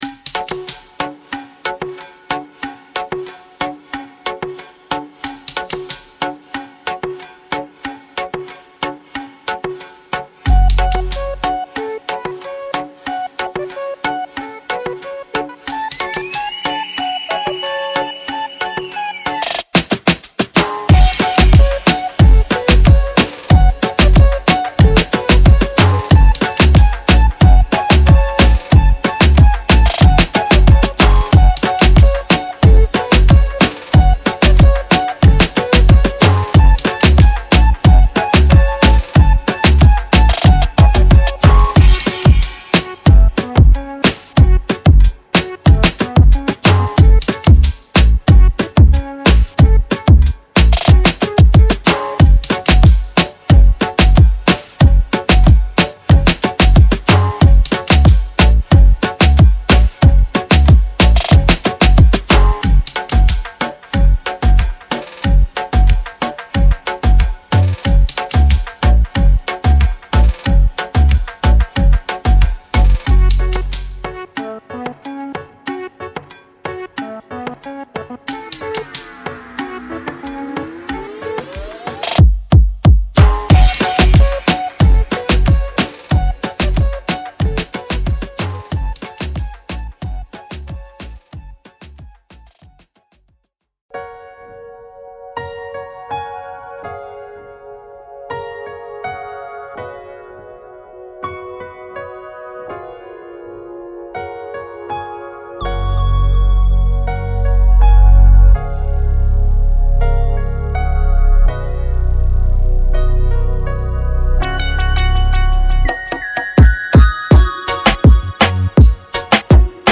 • All Loops Include Dry & Wet Versions
• 6 Piano Loops
• 6 Acoustic Guitar Loops
• 2 Accordion Loops
• 4 Brass Loops